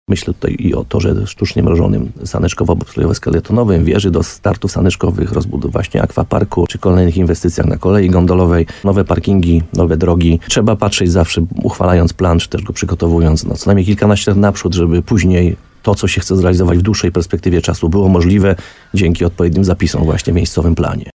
– Dzięki temu otworzy się przestrzeń pod inwestycje na najbliższych kilkanaście lat – mówił w programie Słowo za Słowo na antenie RDN Nowy Sącz burmistrz uzdrowiska Piotr Ryba.